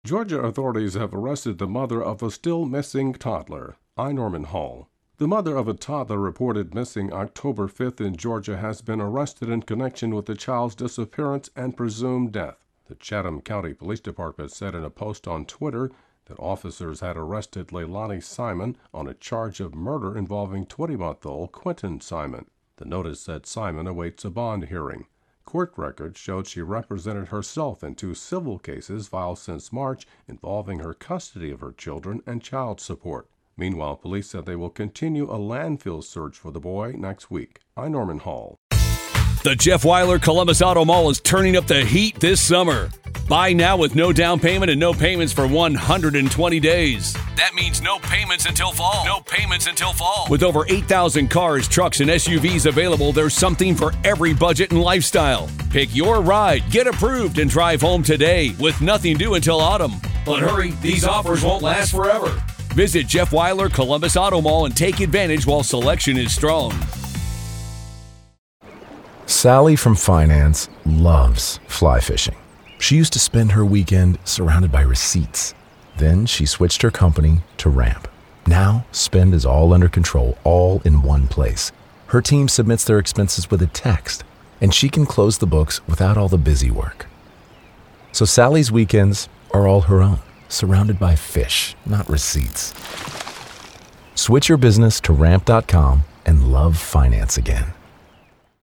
Georgia authorities have arrested the mother of a still-missing toddler. AP correspondent